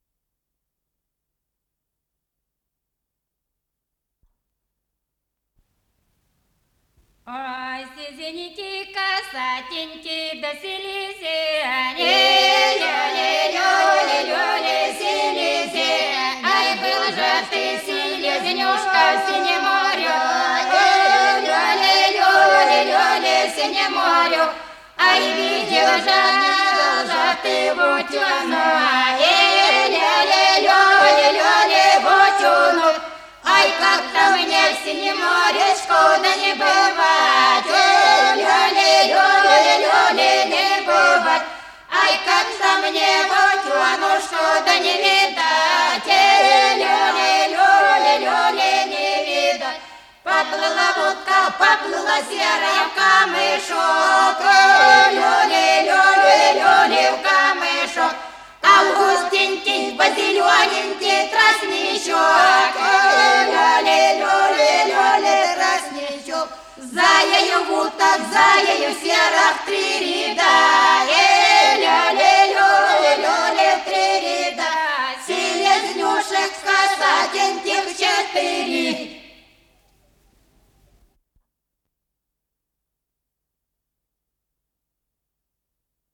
КомпозиторыРусская народная песня
ИсполнителиАнсамбль певцов села Плёхово Суджанского района Курской области
ВариантДубль моно